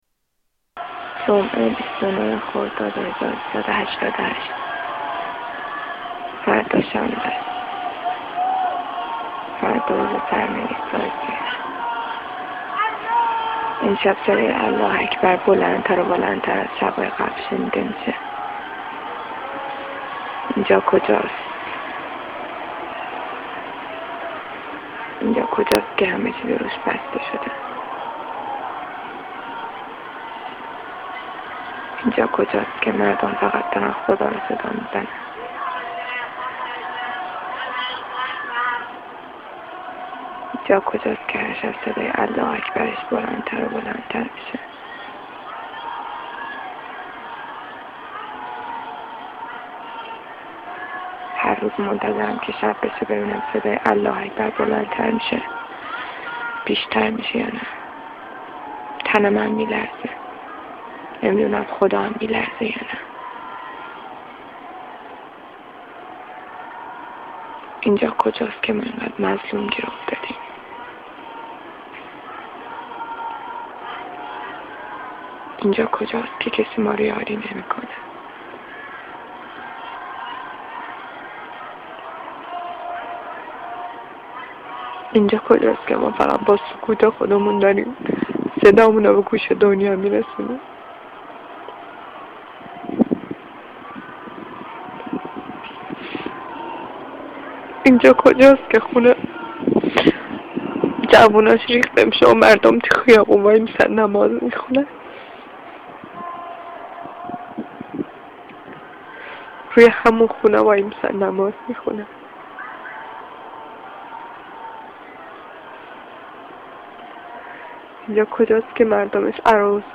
Poem from the rooftop